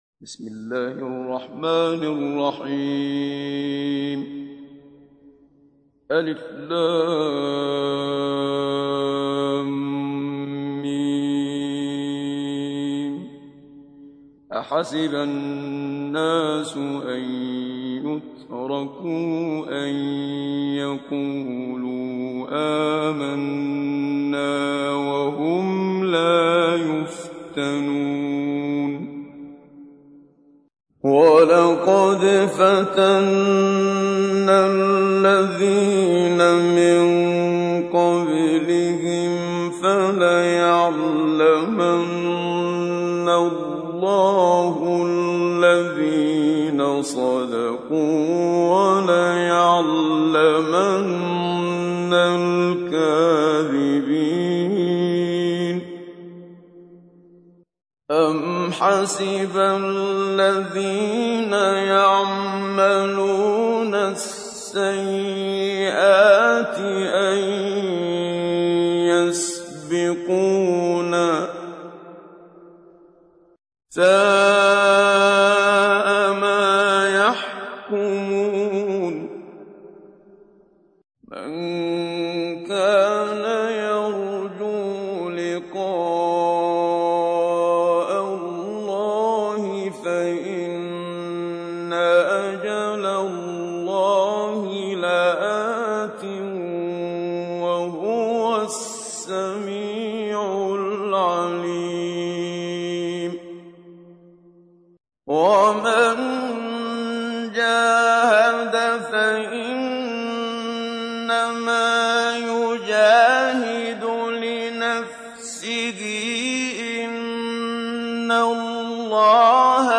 تحميل : 29. سورة العنكبوت / القارئ محمد صديق المنشاوي / القرآن الكريم / موقع يا حسين